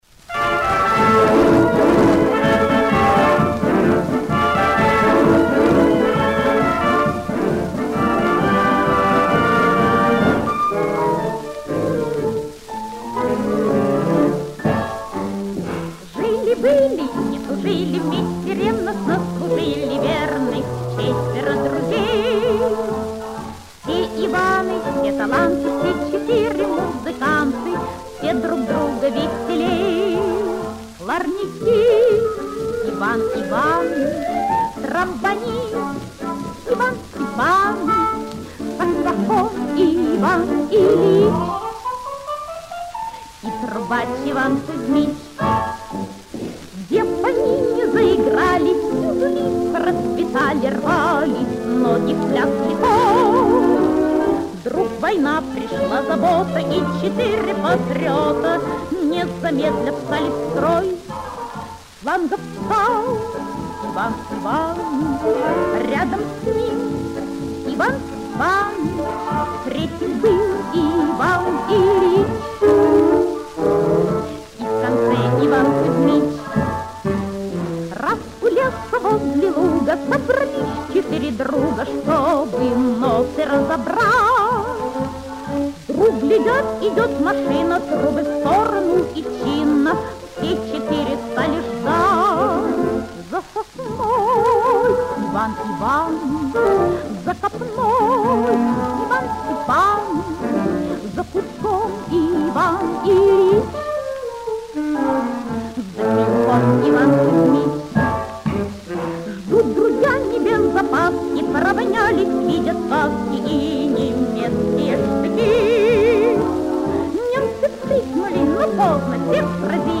Каталожная категория: Сопрано с джаз-оркестром
Жанр: Песня
Вид аккомпанемента: Джаз-ансамбль
Место записи: Ленинград